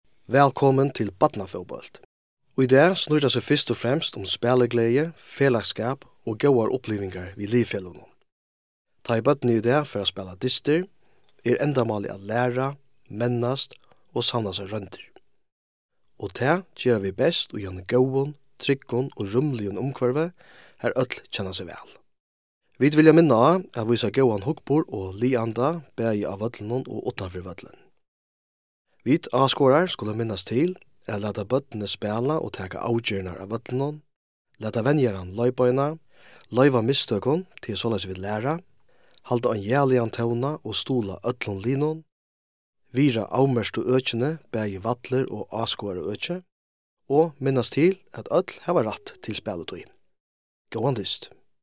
Upplestur til barnakappingar